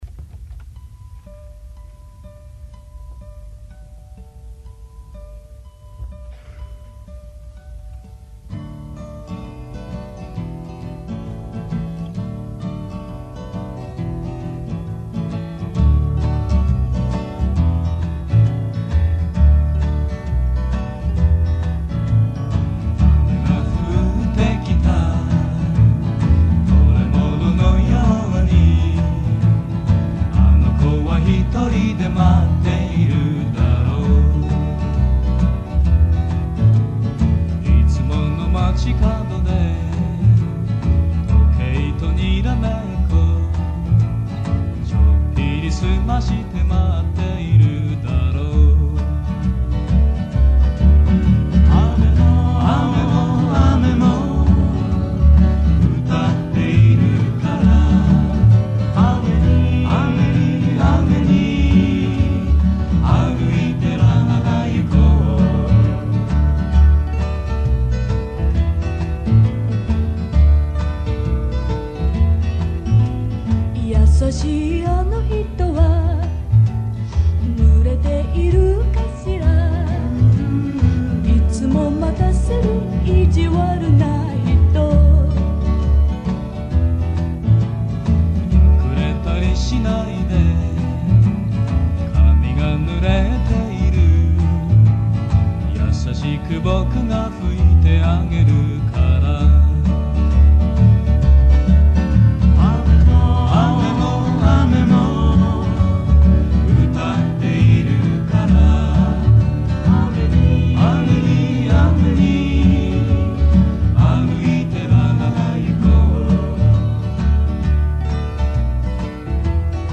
１９６８年サンケイホールのライブで